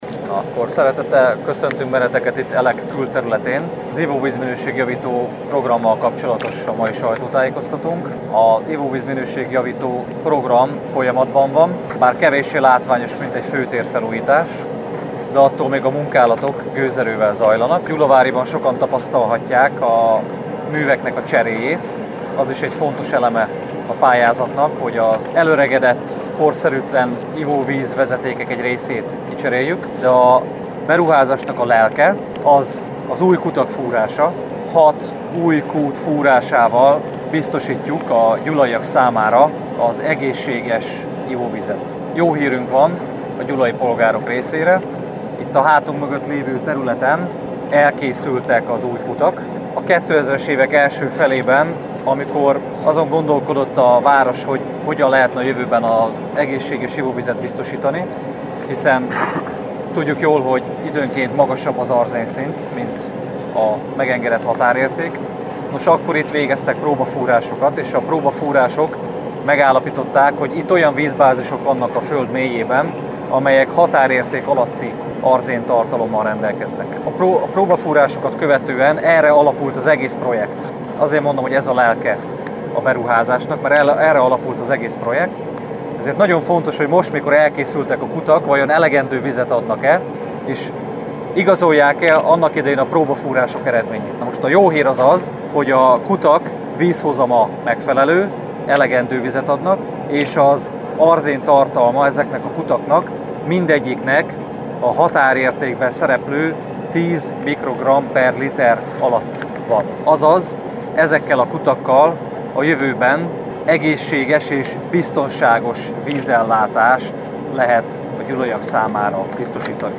Ivóvízminőség-javító program Gyulán. A sajtótájékoztató vágatlan felvétele.